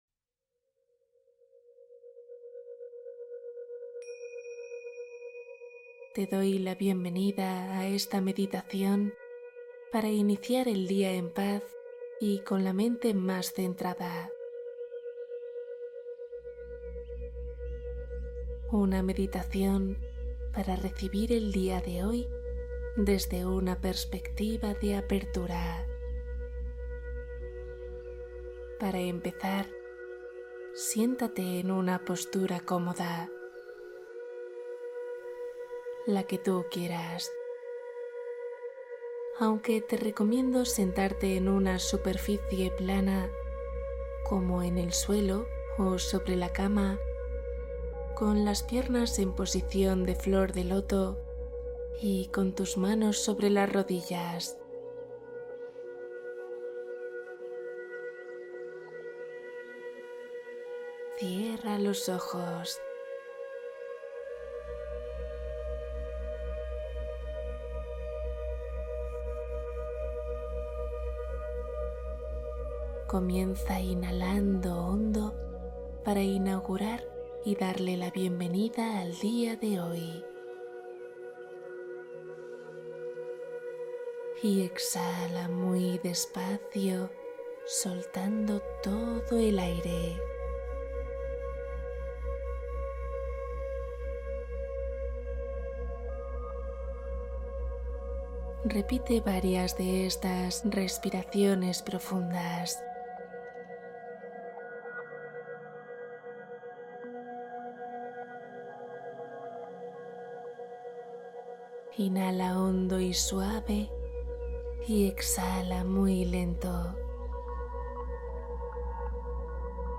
Te hablo hasta que duermas Meditación suave y profundamente relajante